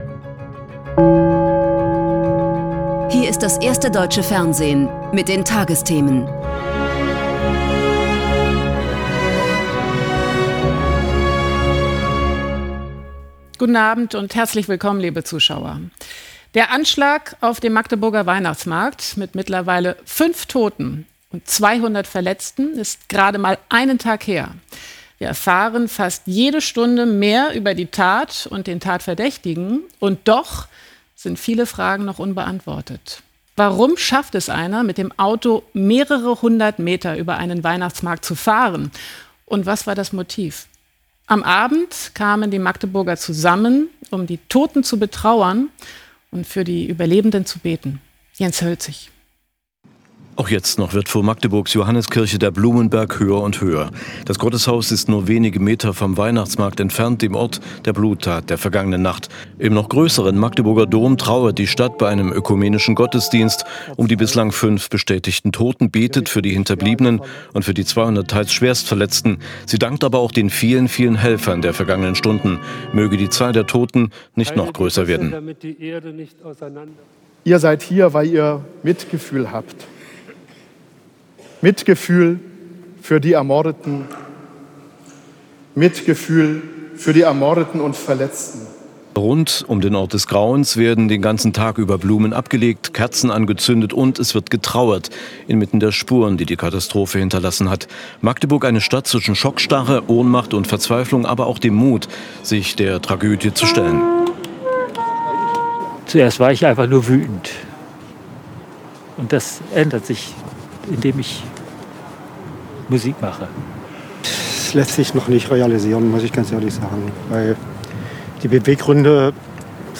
… continue reading 2651 episoder # Tägliche Nachrichten # Nachrichten # Tagesschau